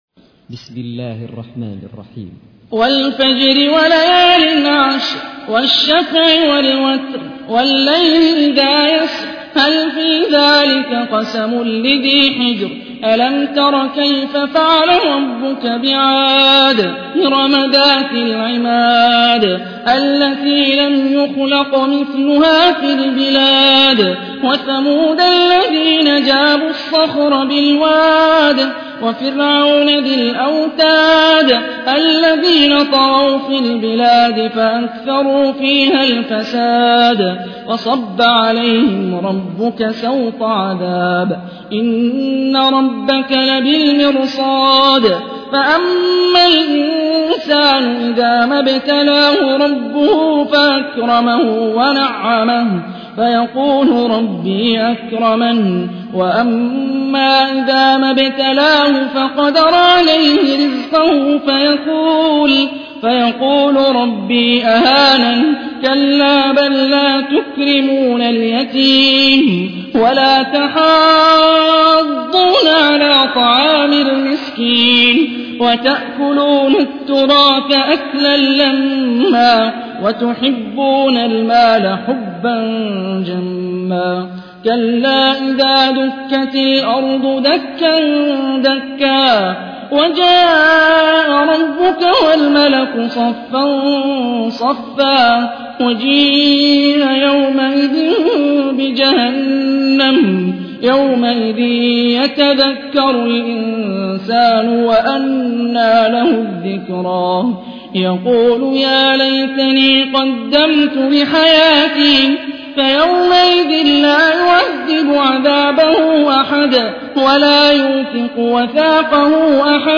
تحميل : 89. سورة الفجر / القارئ هاني الرفاعي / القرآن الكريم / موقع يا حسين